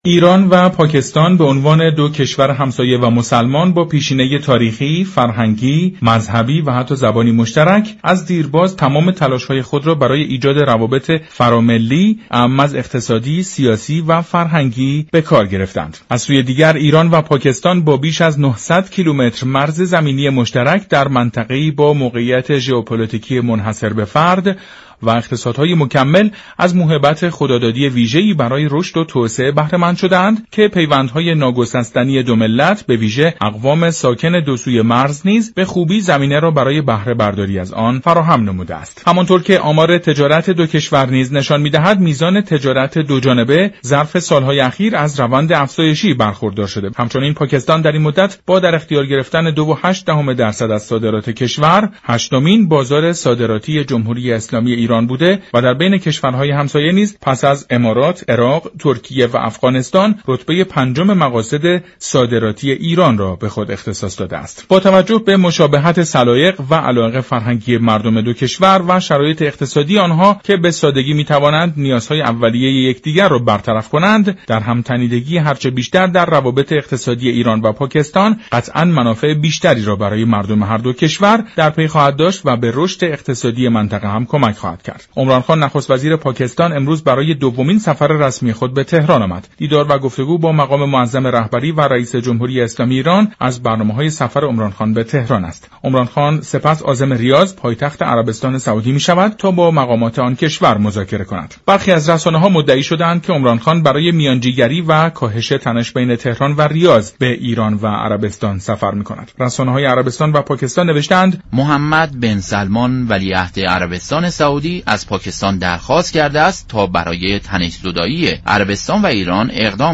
به گزارش شبكه رادیویی ایران، برنامه «جهان سیاست» برای تحلیل بیشتر با «محسن روحی صفت» ، معاون پیشین دفتر مطالعات سیاسی وزارت خارجه و دیپلمات پیشین ایران در پاكستان گفت و گو كرده است.